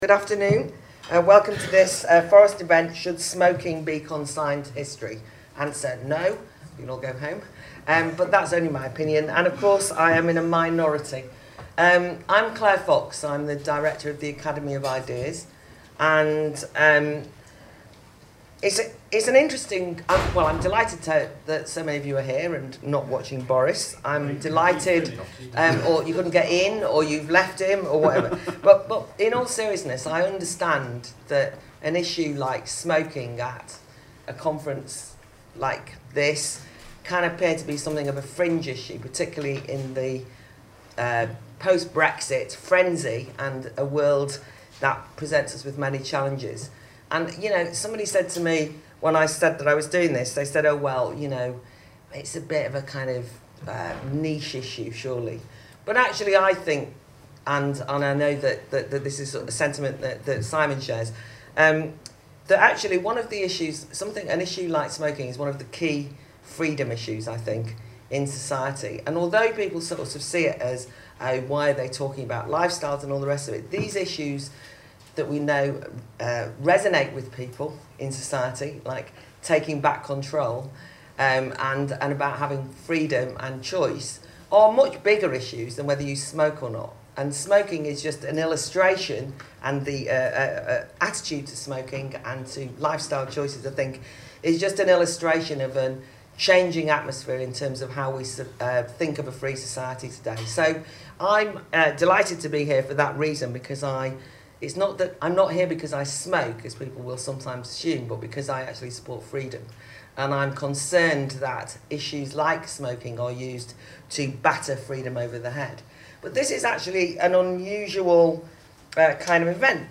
Two weeks ago Forest hosted a fringe meeting at the Conservative party conference in Birmingham.